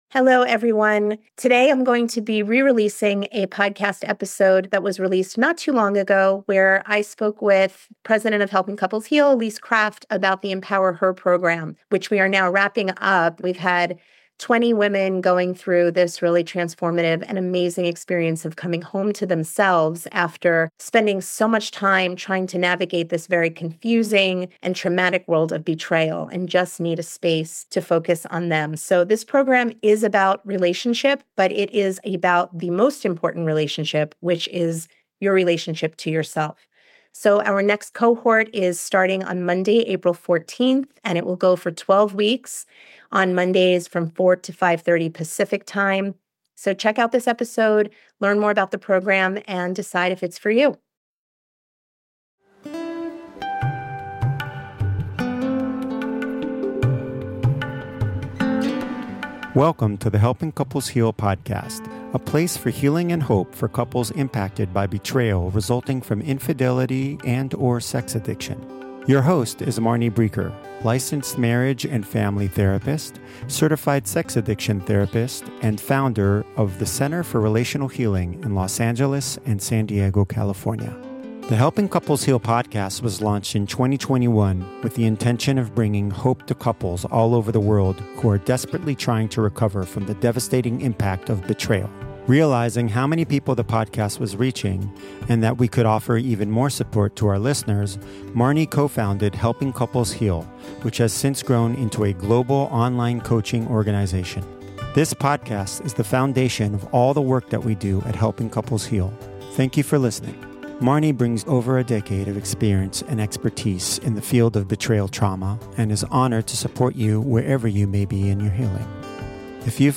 You will also hear from recovered addicts, partners, and couples who will share their stories, as well as from experts in the field of betrayal.